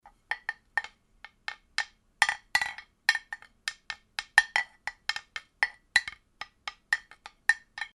• ear candy porcelain mug hits.mp3
Recorded with a Steinberg Sterling Audio ST66 Tube, in a small apartment studio.
ear_candy_porcelain_mug_hits_2rk.wav